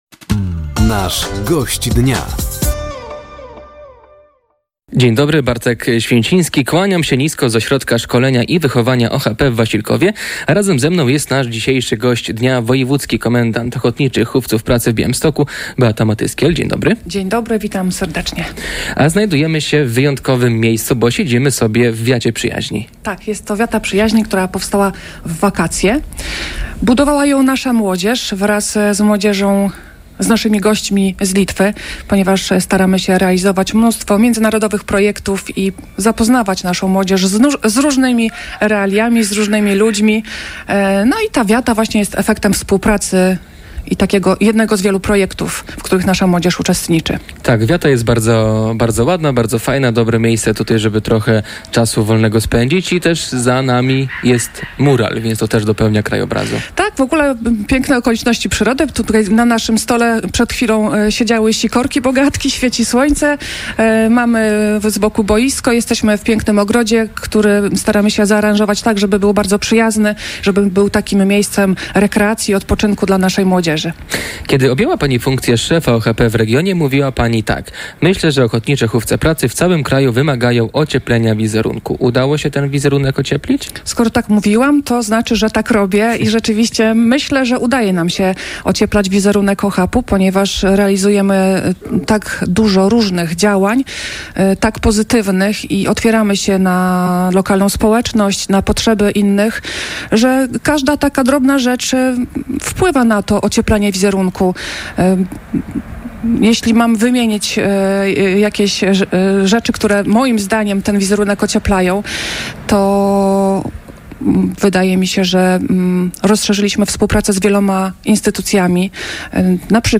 Gościem Dnia Radia Nadzieja była Beata Matyskiel, Wojewódzki Komendant OHP w Podlaskim. Rozmowa